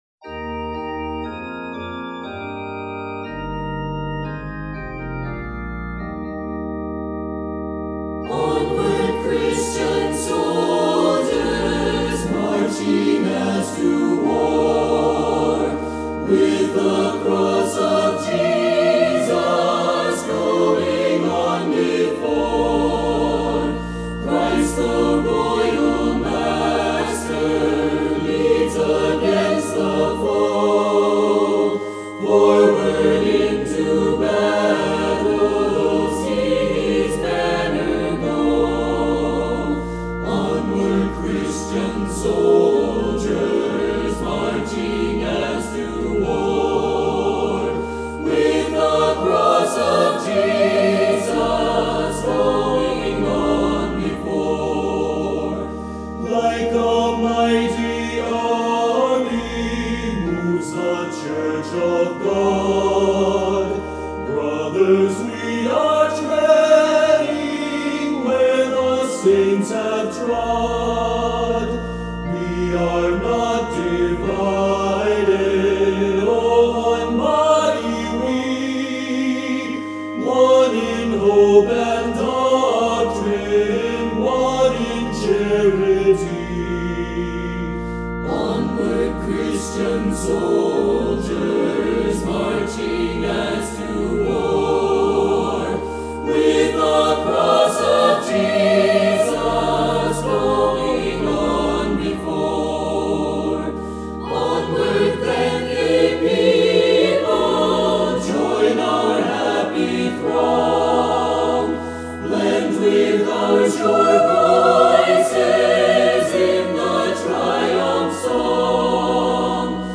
JOSLIN GROVE CHORAL SOCIETY: